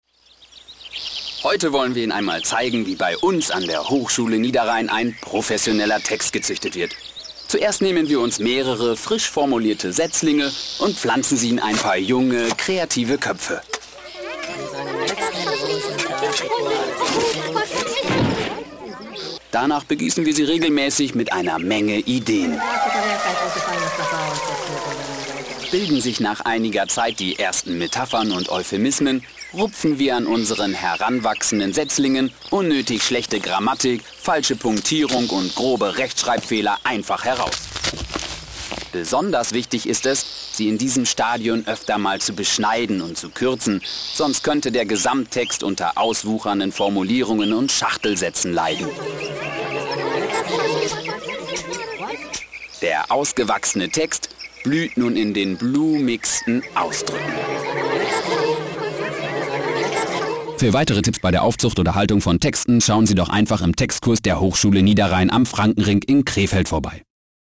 Am Dienstag hatten wir nun die Möglichkeit unsere Radiospots im Sprachlabor in Düsseldorf mit professionellen Sprechern aufzunehmen.
Radiospot – Kurze Version – 1 MB / MP3(rechte Maustaste/Speichern unter…)